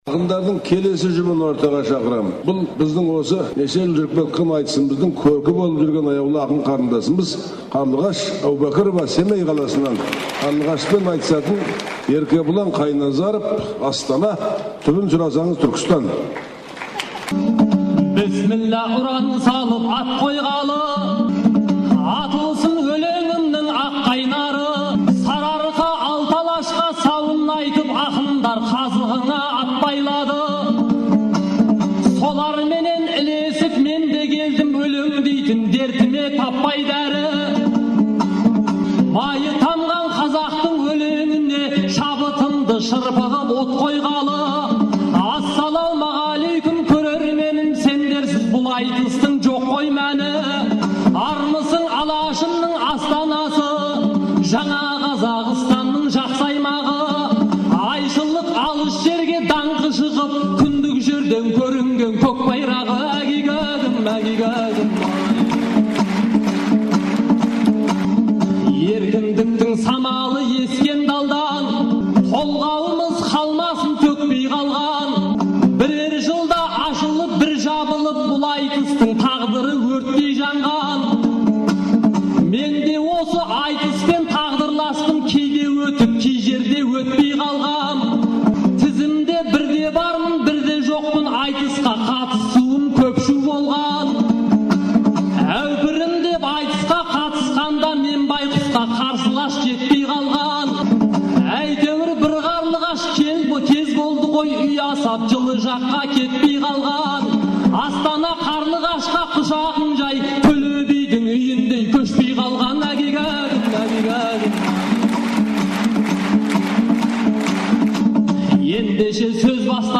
«Тәуелсіздік тағылымы» айтысының екінші күні көрермен алдына шыққан екінші жұп